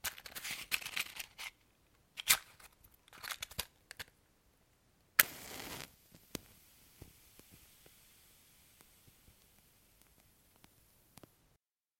声景高级版 打击火柴 1
描述：罢工的FX音景。用H2next录制的。我无法在不破坏干净声音的情况下过滤背景噪音。